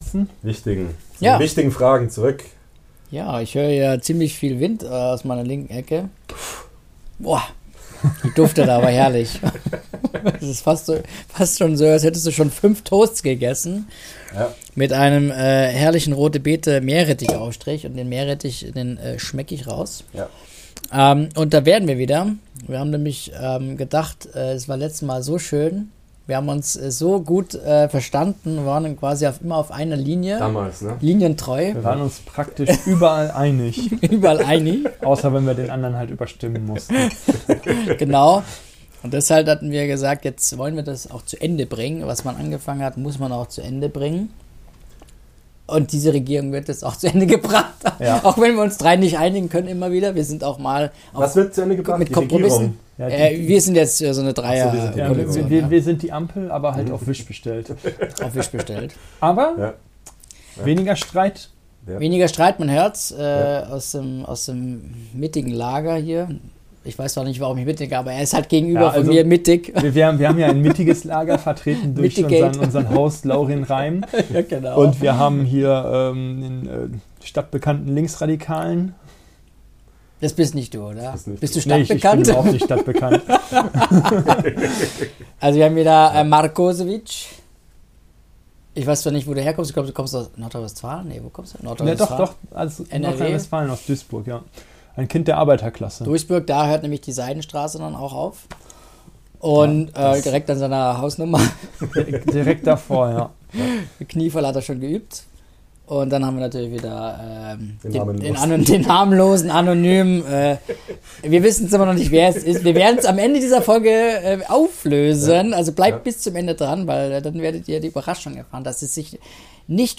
Hier der zweite Teil unserer hitzigen Diskussion im Vorfeld der anstehenden Wahl.